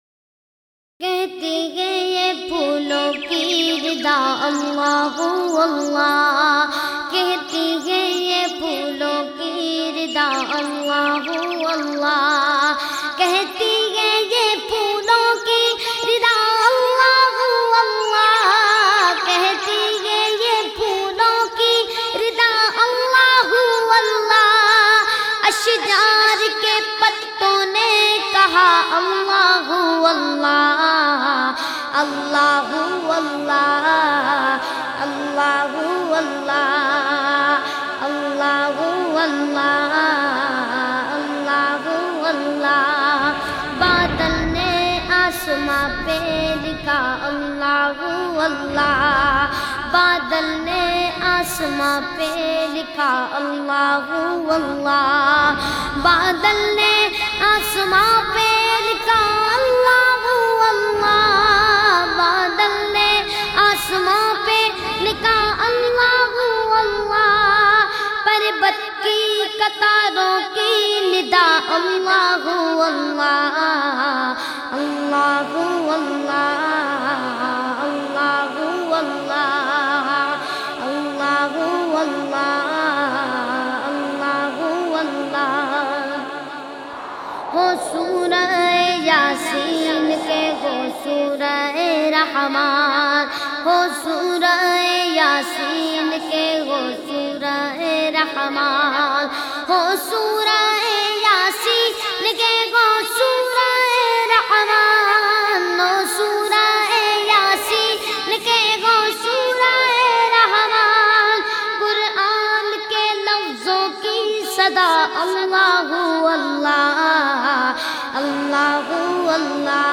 Naat MP3